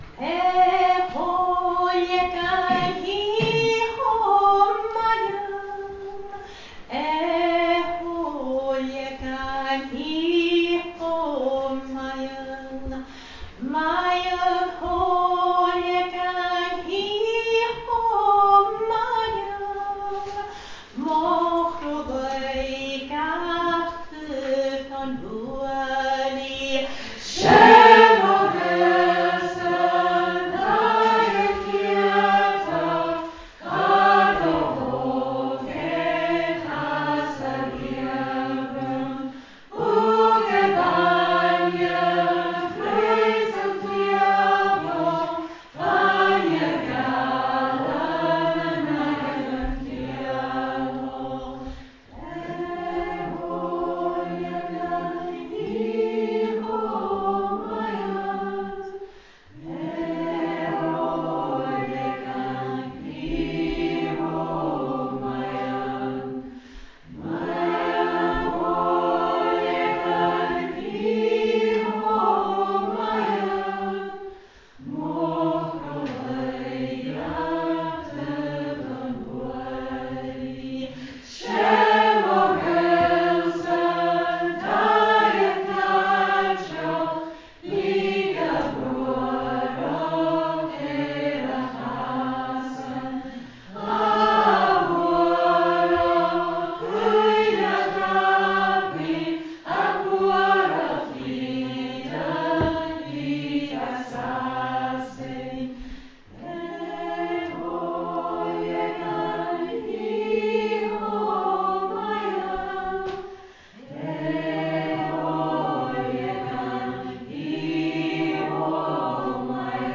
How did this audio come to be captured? Here are recordings from the cèilidh on Thursday night when we sang a some of the songs we learnt: